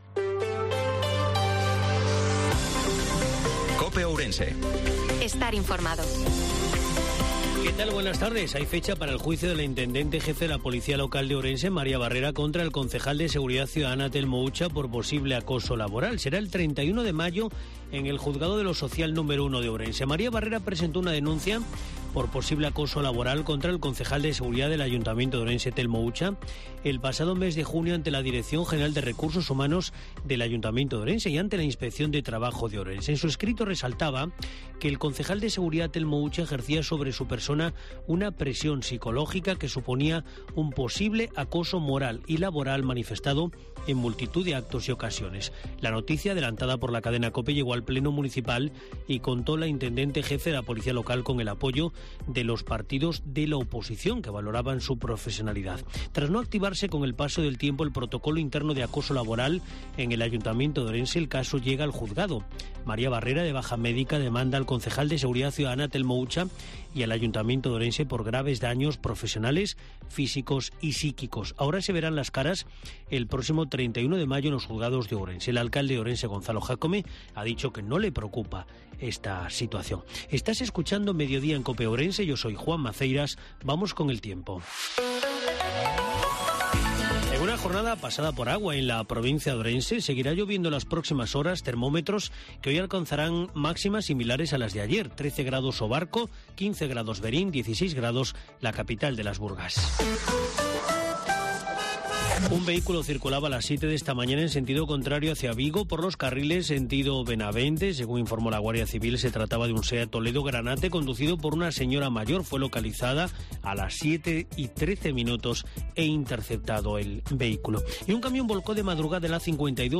INFORMATIVO MEDIODIA COPE OURENSE-13/04/2023